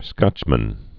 (skŏchmən)